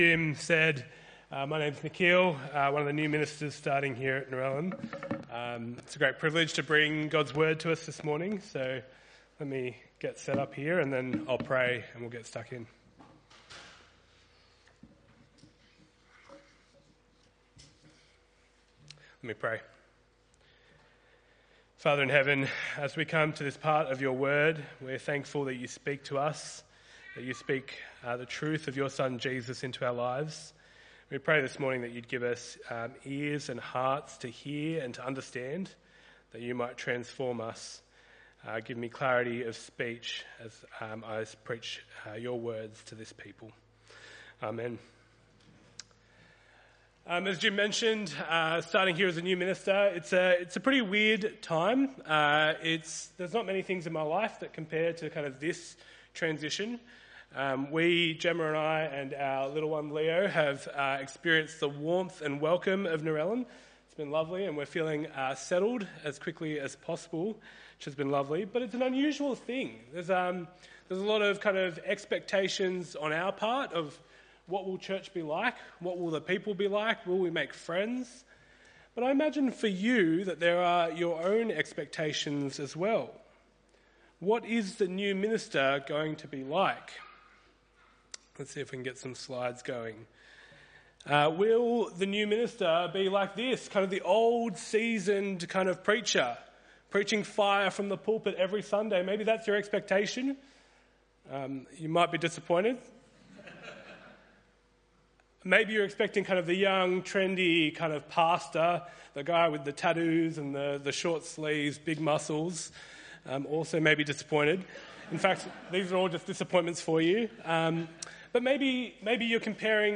Sunday 28 December 2025 - Narellan Anglican Church